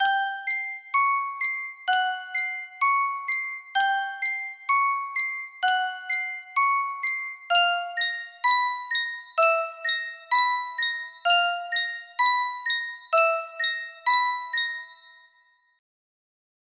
Yunsheng Music Box - Creepy Music Box 4224 music box melody
Full range 60